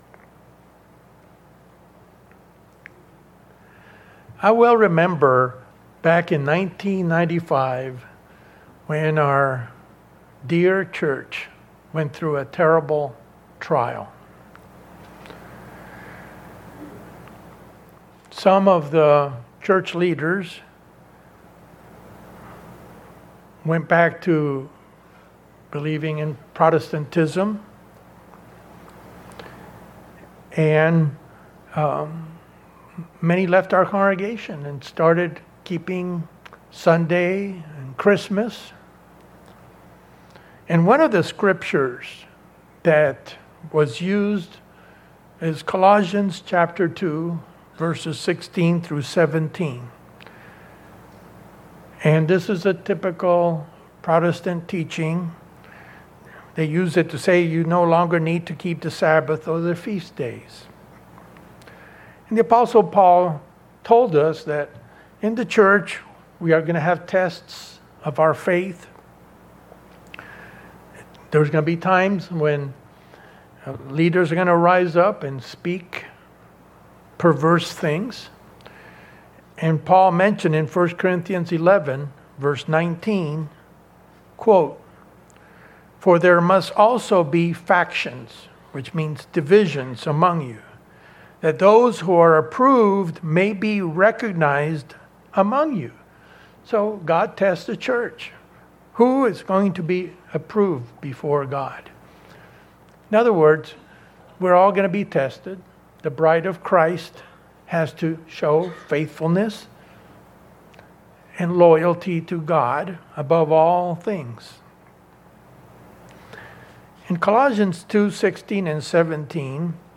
In this message, we explore four biblical proofs that address the pagan heresy brought out in Paul's epistle to the Colossians concerning feast day observances.